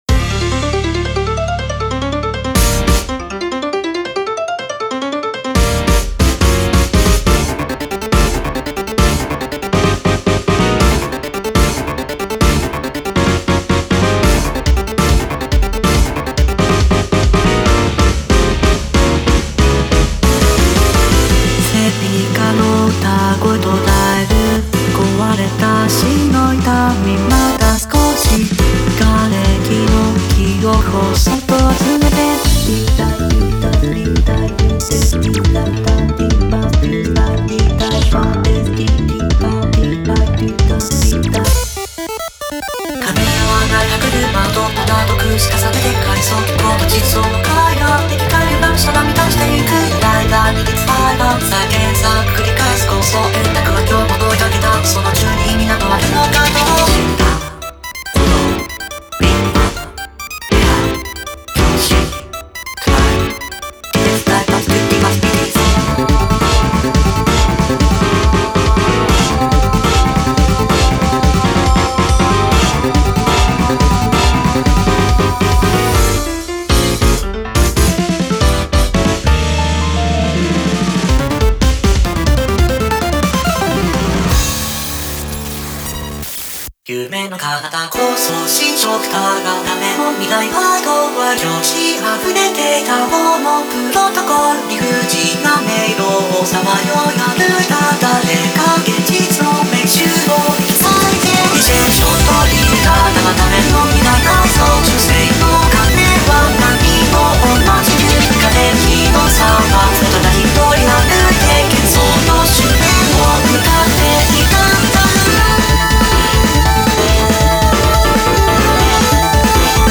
BPM140
Audio QualityPerfect (High Quality)
Somewhat chaotic song at points